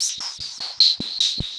RI_RhythNoise_150-04.wav